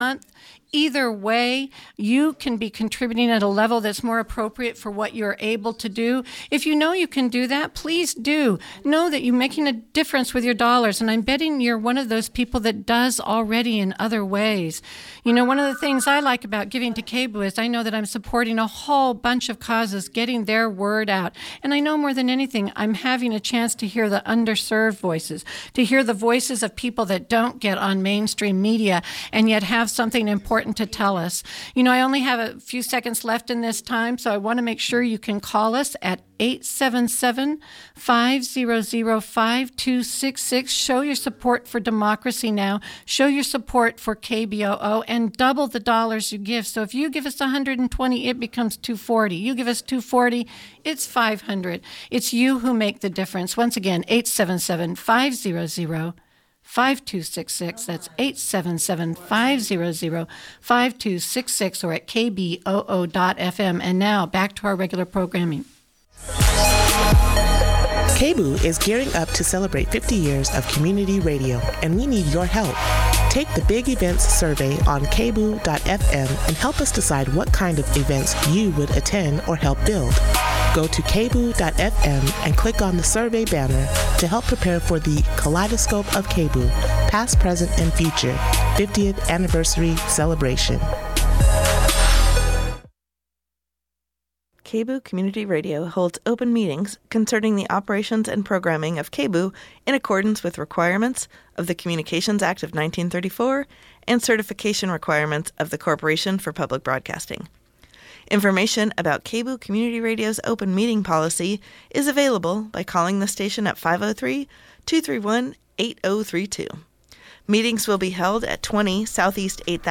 Then, it's a rebroadcast of an interview with Fred Hampton JR on Hard Knock Radio. play pause mute unmute KBOO